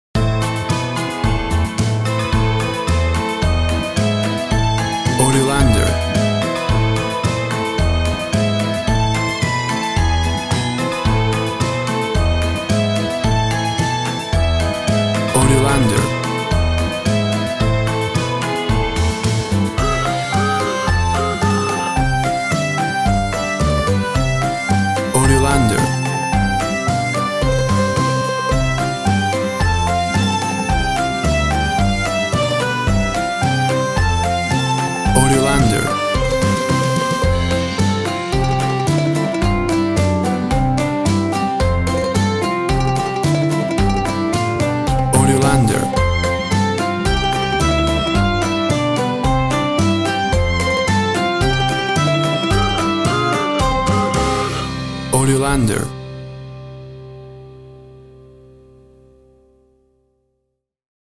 Tempo (BPM) 110